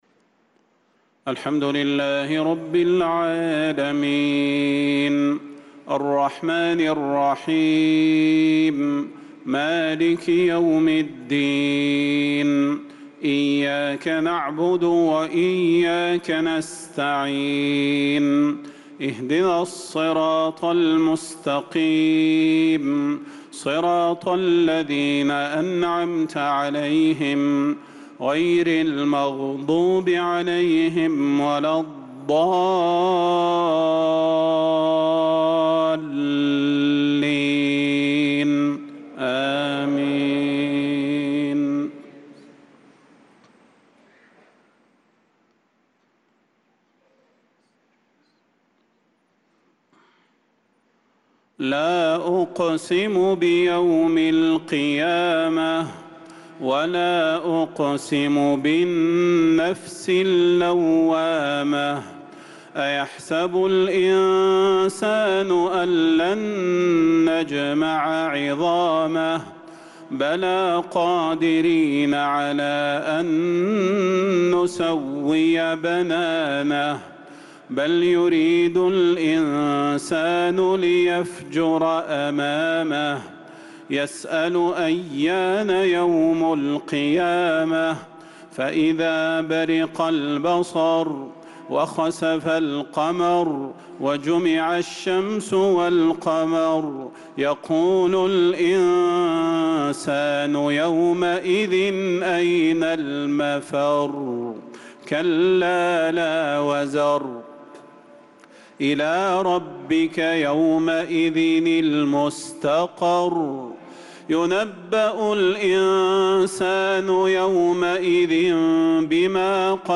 صلاة العشاء للقارئ صلاح البدير 28 ذو الحجة 1445 هـ